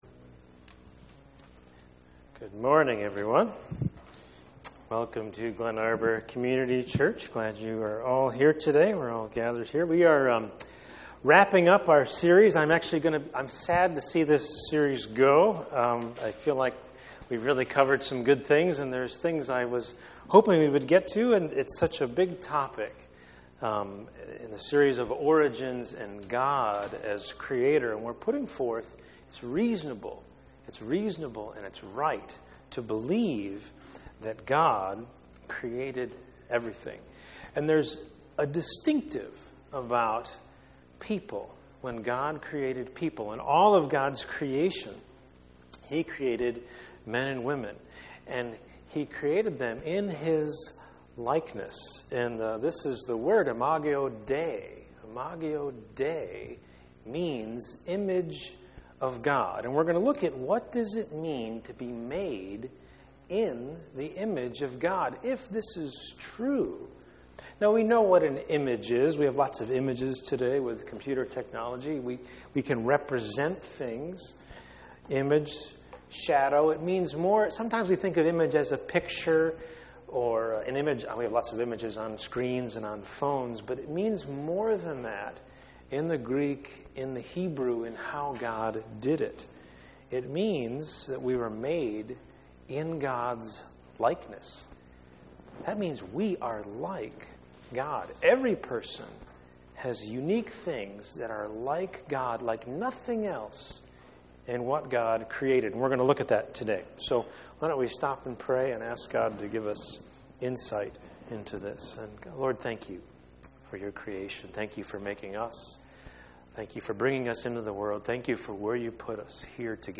Creation Series Service Type: Sunday Morning %todo_render% « The Great Commission Dissected